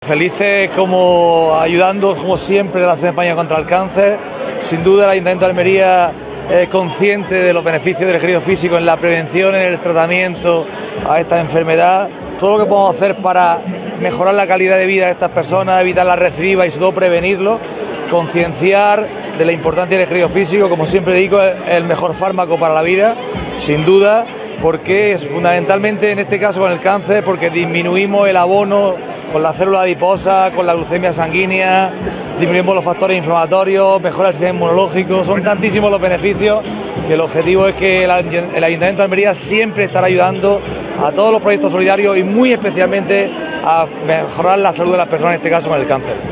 Antonio-Jesus-Casimiro-Concejal-Ciudad-Activa-Carrera-contra-el-Cancer.wav